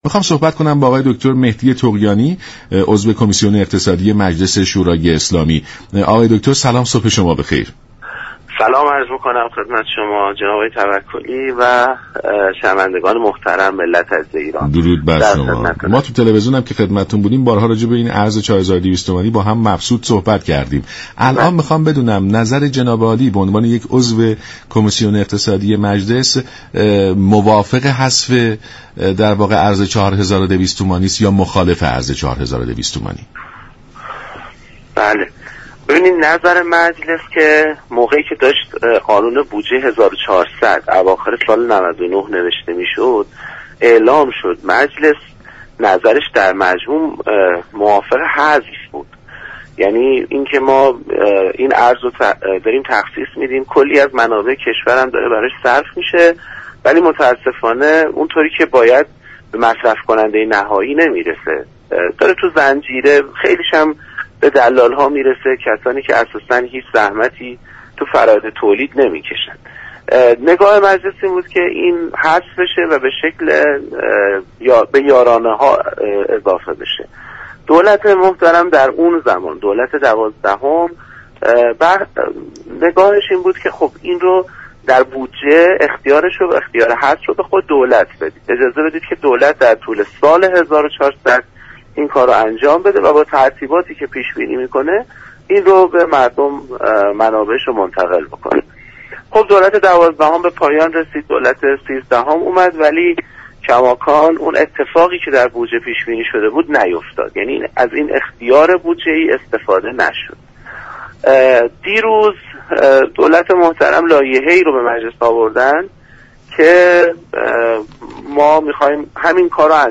به گزارش شبكه رادیویی ایران، مهدی طغیانی عضو كمیسیون اقتصادی مجلس در برنامه سلام صبح بخیر رادیو ایران به بحث حذف ارز 4200 تومانی پرداخت و گفت: ارز 4200 تومانی بیشتر از آنكه به نفع مصرف كننده نهایی باشد به نفع سودجویان و واسطه گران است؛ مجلس از همان زمان بررسی بودجه سال 1400، با حذف ارز 4200 تومانی، موافق بوده است.